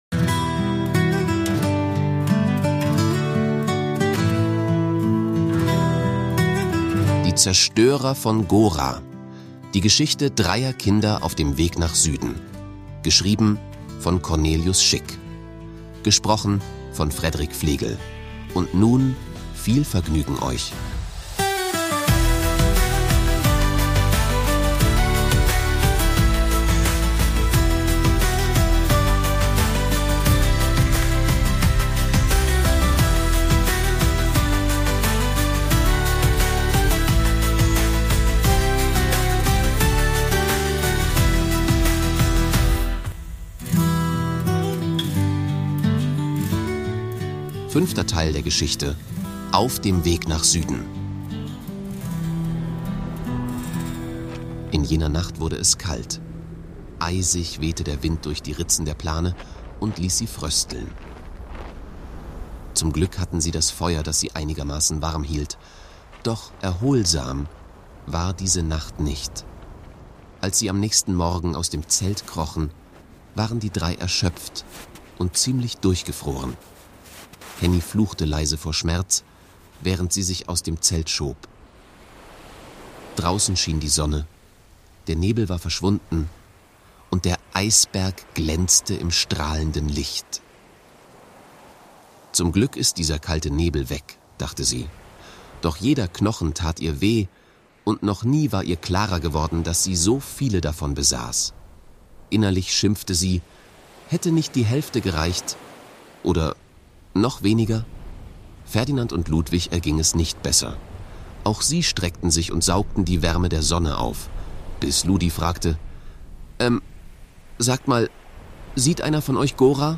Folge 5: Auf dem Weg nach Süden Fantasy-Hörbuch mit Sounddesign | Ab 12 Jahren | Deutsch Auf der Flucht nach Süden stranden Henriette, Ferdinand und Ludwig auf einem treibenden Eisberg.